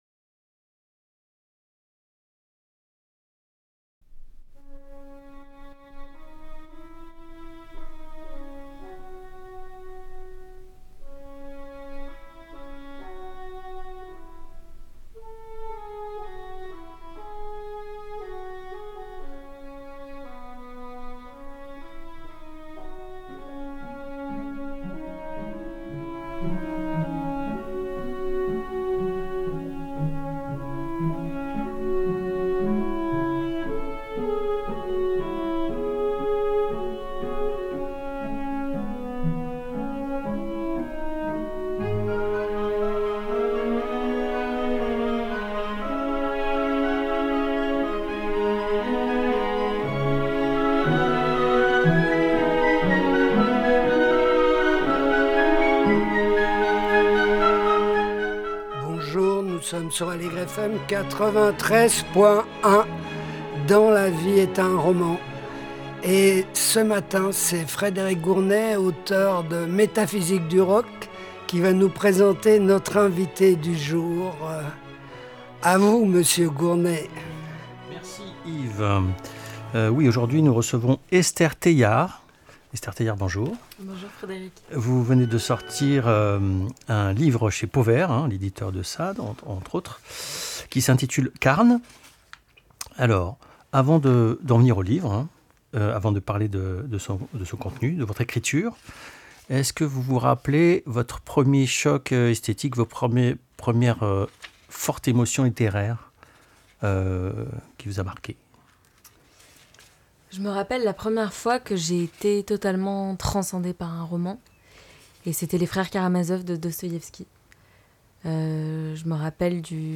Elle est interviewée